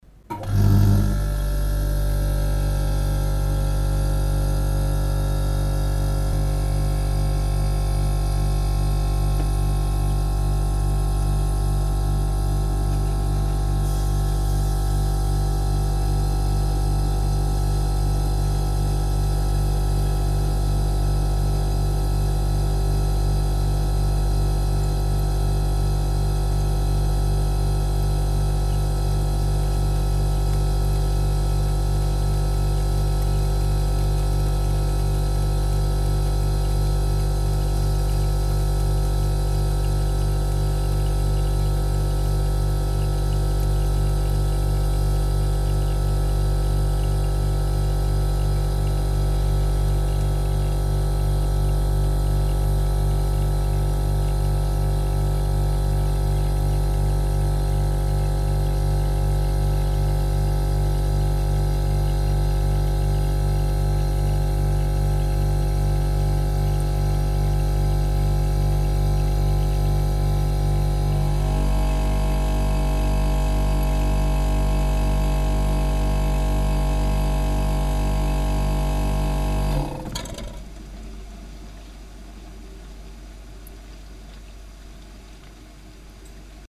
Звуки холодильника
На этой странице собраны звуки работающего холодильника: от монотонного гула до характерных щелчков и бульканья хладагента.